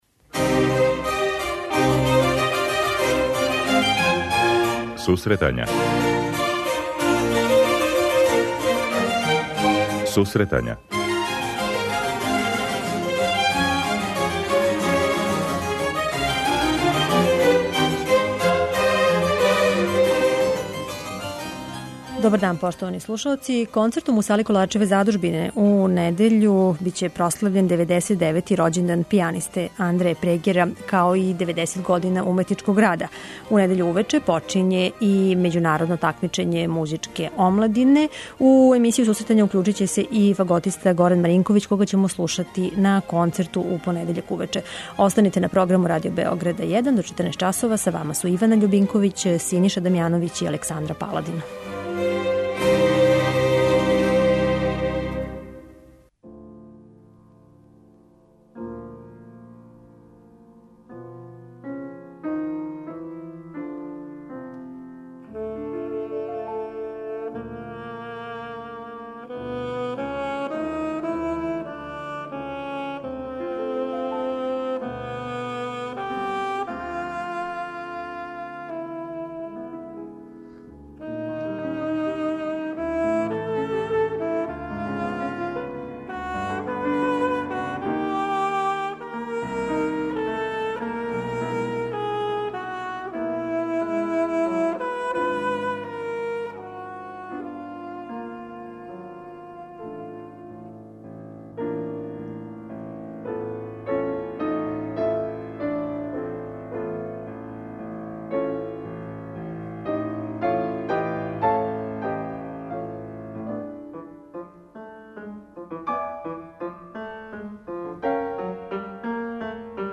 преузми : 23.89 MB Сусретања Autor: Музичка редакција Емисија за оне који воле уметничку музику.